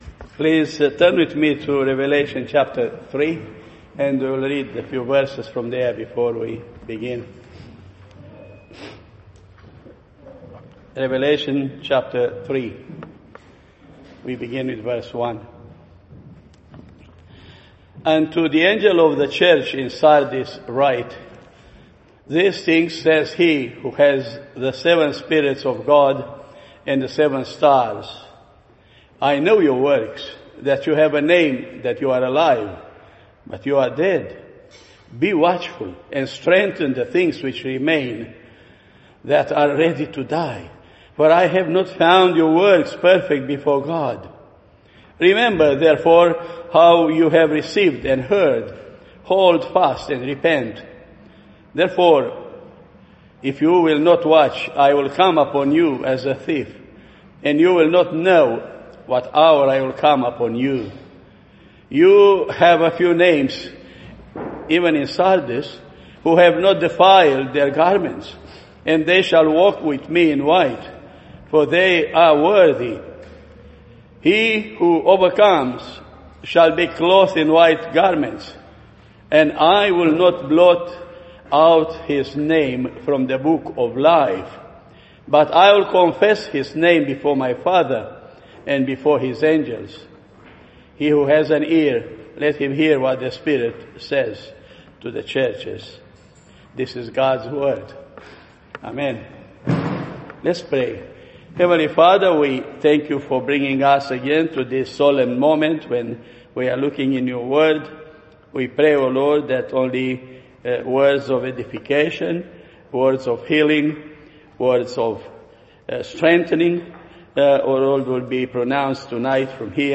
Revelation Passage: Revelation 3:1-6 Service Type: Sunday Evening « The Steps of a Good Man are Ordered by the Lord